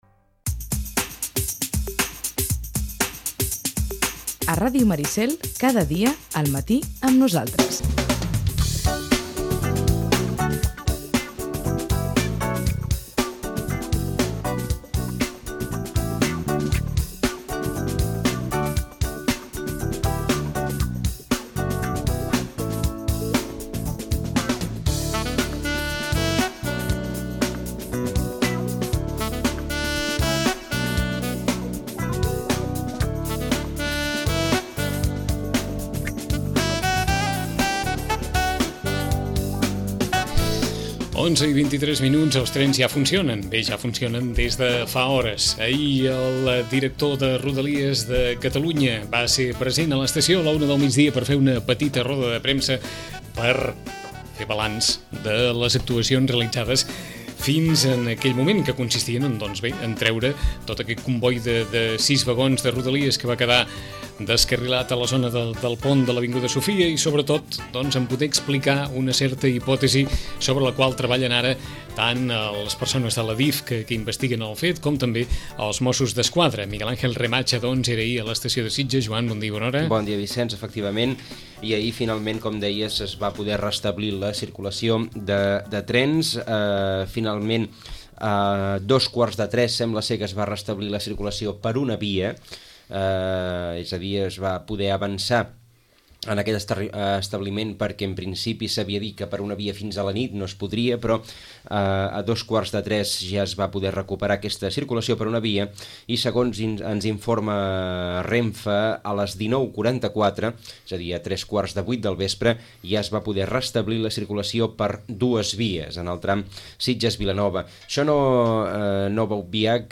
En roda de premsa